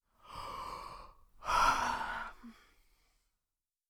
yawn2.wav